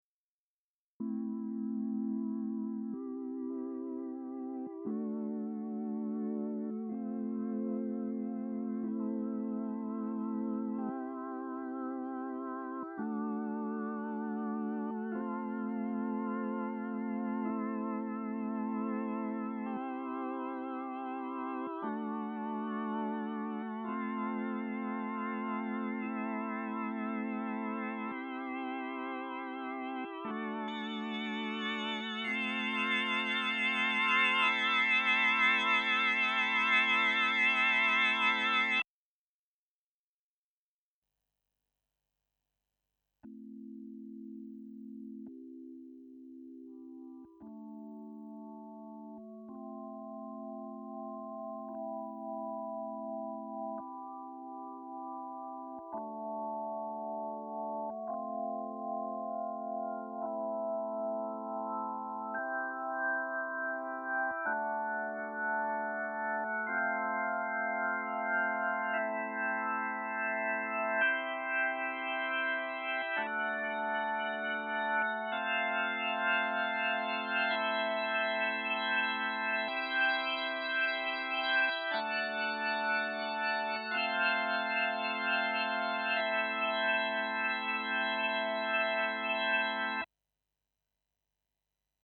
Dazu folgende Anmerkungen: Bis auf Hörbeispiel 14 ist immer zunächst der Nord Electro 5D zu hören, dann das Yamaha YC61 Stage Keyboard.
8. Nord Electro 5D Vox-Emulation und YC61 F1, Zugriegel nacheinander